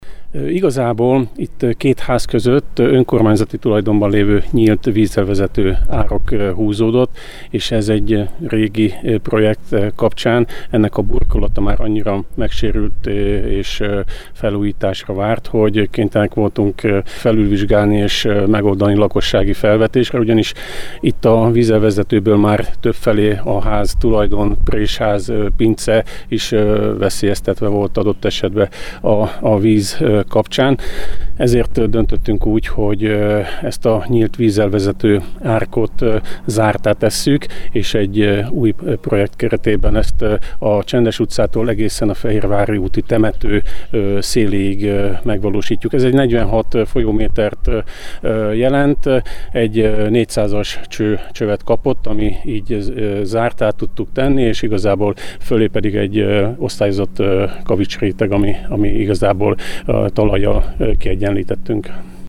Egy önkormányzati tulajdonban lévő ingatlanon található nyílt, burkolt csapadékvíz elvezető árok zárt rendszerre való átépítése vált szükségessé, ugyanis a régi nyílt árok intenzív esőzéskor a megnövekedett csapadékvízhozamot nem tudta biztonságosan továbbítani. Horváth Zoltán, a körzet önkormányzati képviselője a projekt műszaki átadásán elmondta, fontos beruházást hajtottak végre.